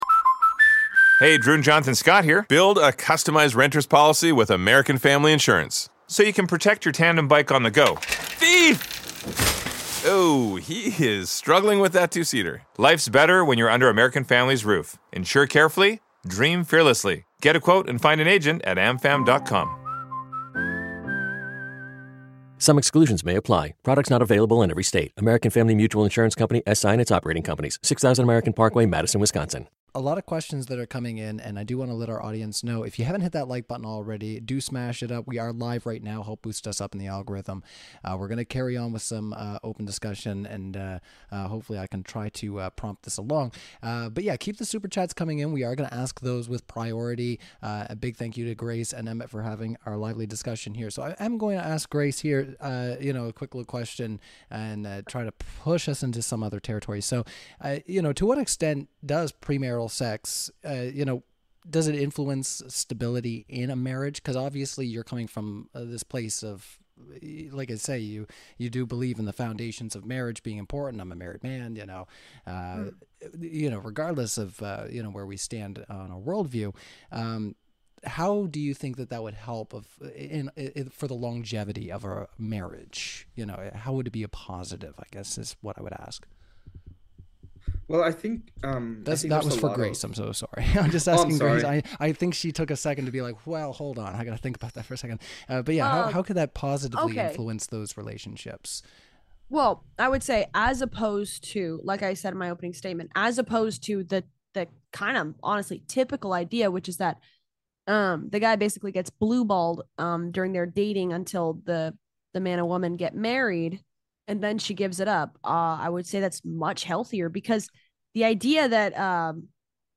We believe debates should be moderated on an equal playing field and host live debates on controversial topics from science, religion, and politics several times a week.